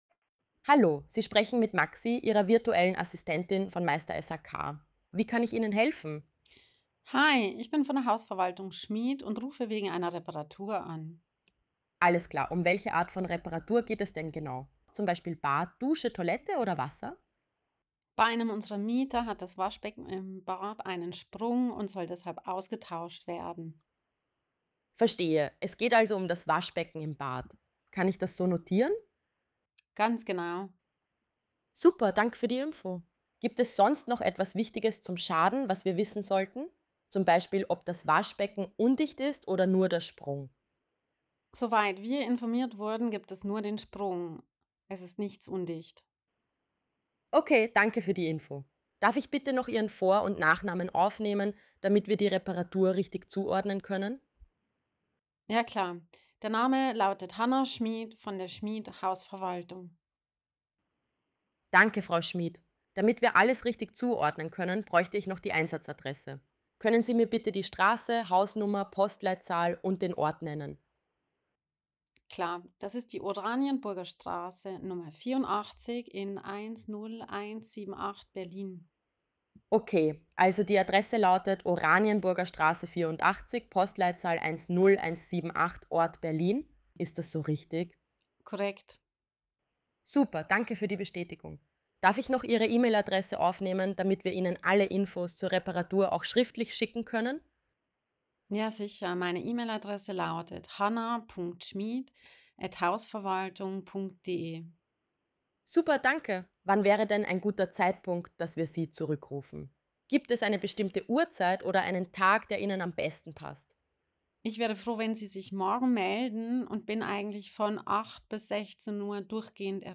Frag Maxi - die KI-Telefonassistentin für Handwerksbetriebe
So klingt ein Anruf bei Maxi.